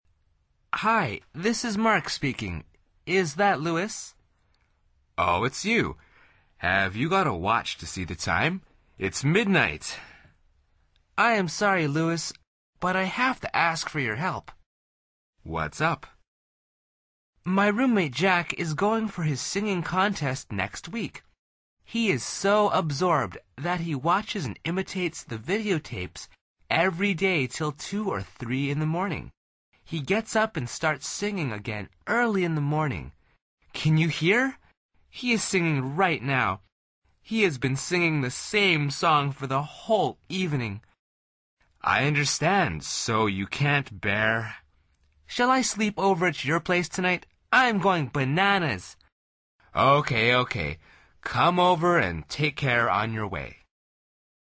Pulsa las flechas de reproducción para escuchar el segundo diálogo de esta lección. Al final repite el diálogo en voz alta tratando de imitar la entonación de los locutores.